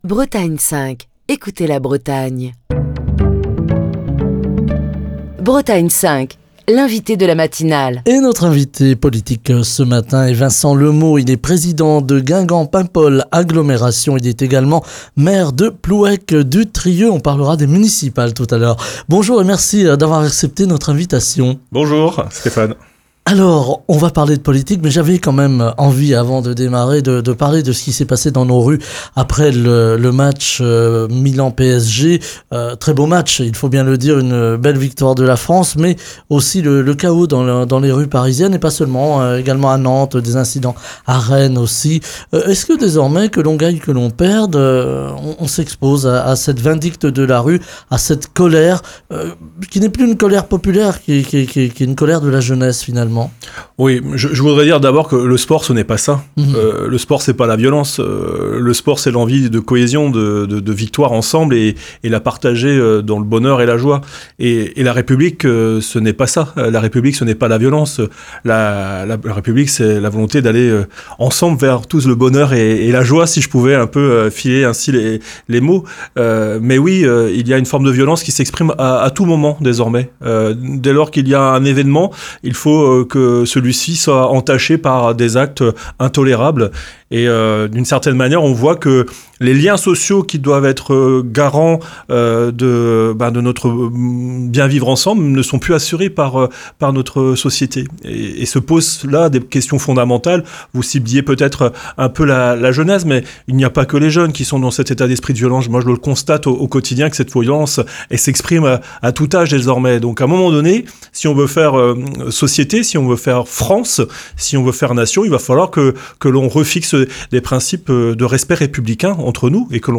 Ce mardi, Vincent Le Meaux, président de Guingamp-Paimpol Agglomération et maire de Plouëc-du-Trieux (Côtes-d’Armor), était l’invité politique de la matinale de Bretagne 5. Au micro de Bretagne 5, Vincent Le Meaux est revenu sur les incidents survenus à Paris et dans plusieurs villes de France, à la suite de la victoire du PSG. Il a fermement condamné ces violences commises par des groupes de jeunes, qu’il estime animés par une idéologie éloignée des valeurs de la République.